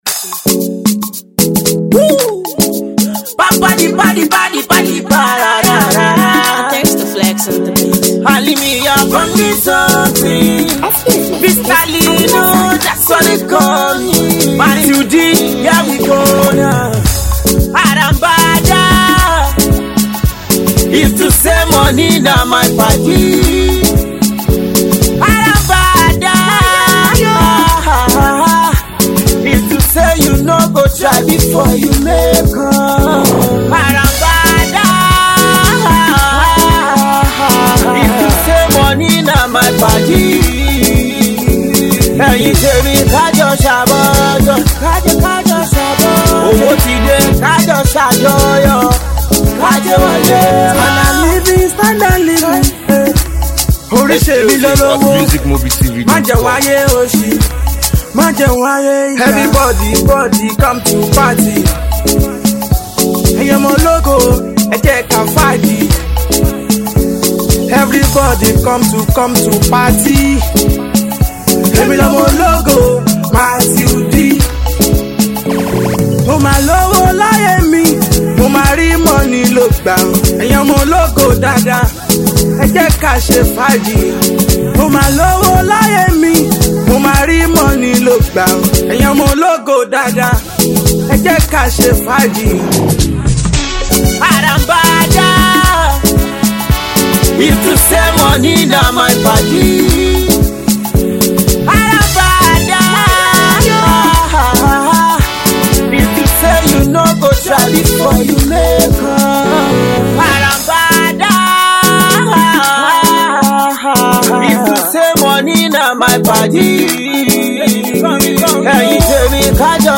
street vibes single